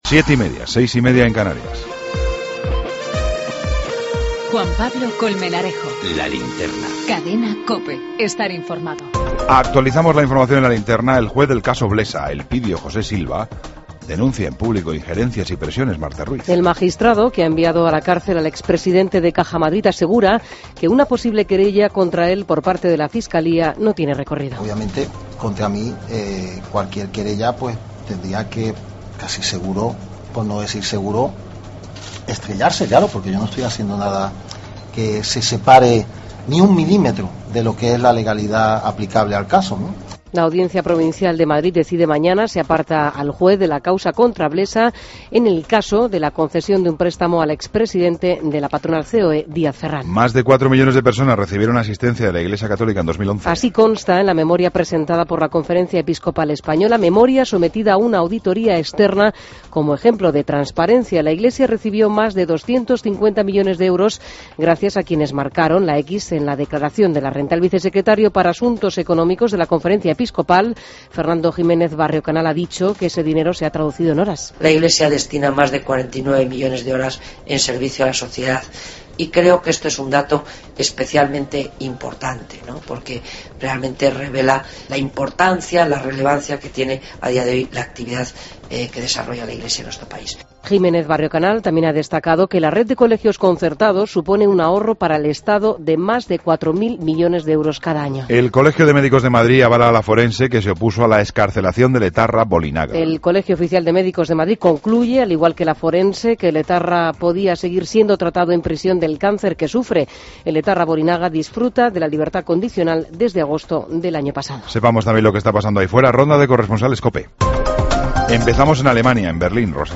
AUDIO: Toda la información con Juan Pablo Colmenarejo. Ronda de corresponsales.